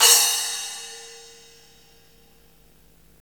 Index of /90_sSampleCDs/Roland L-CD701/CYM_FX Cymbals 1/CYM_Splash menu
CYM SPLAS03R.wav